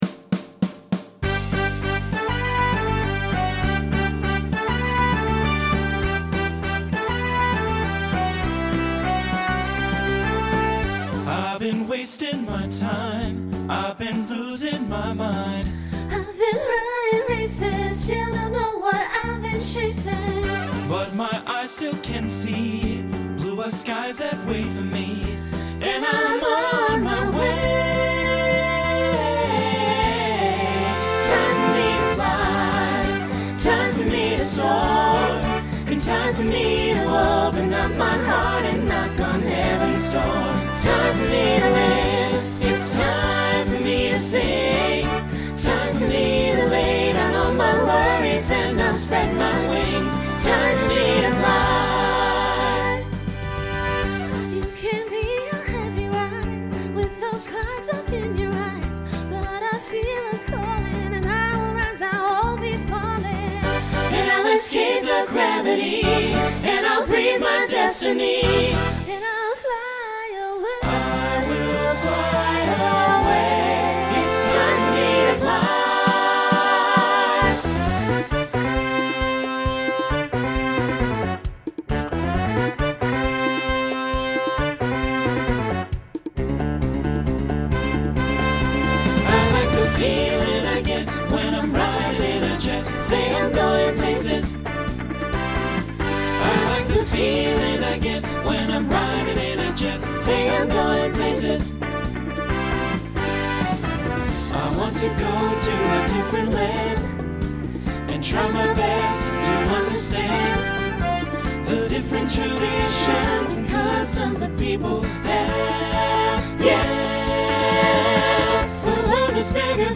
Utilizes a small group to facilitate costume change.
Genre Pop/Dance , Rock
Transition Voicing Mixed